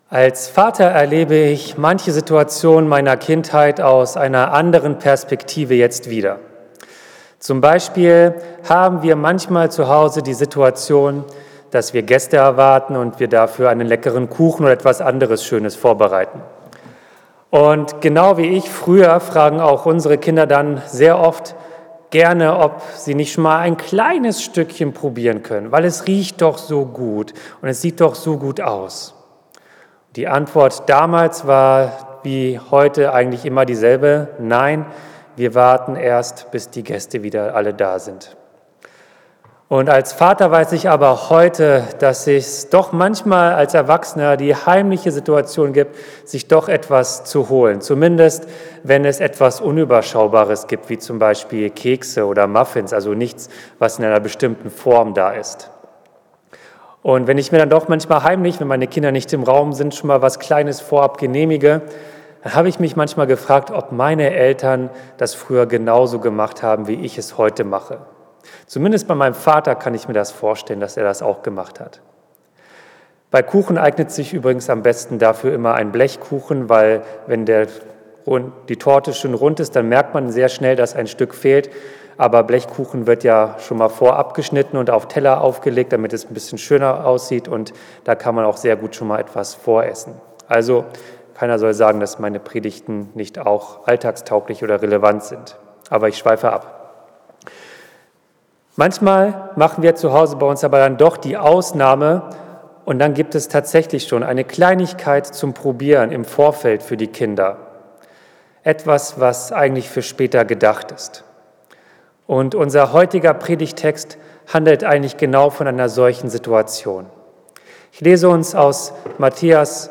Predigt zu Matthäus 15, 21-28 | Bethel-Gemeinde Berlin Friedrichshain